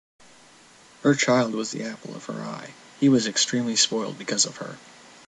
英語ネイティブによる発音は、以下のリンクをクリックしてお聞きください。